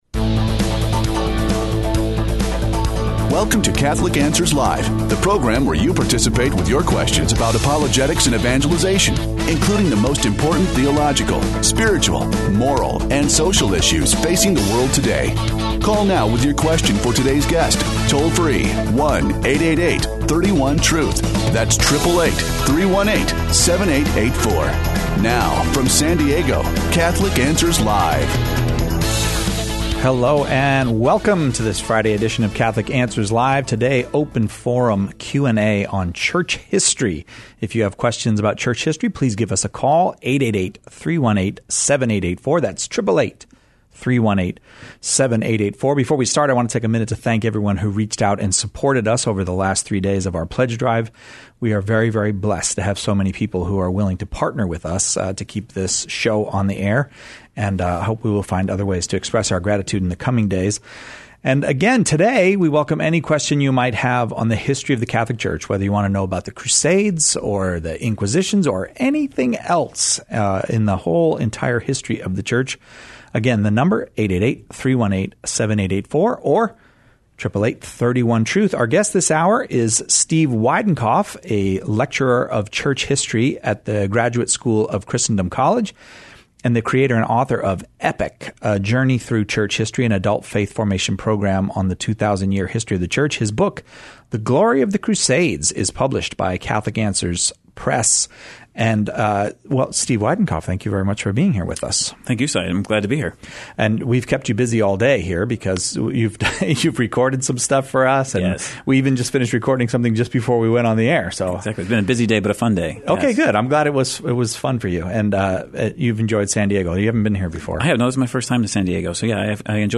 Church history takes center stage as callers pepper our guest with questions on topics ranging from the early Church to modern Church councils.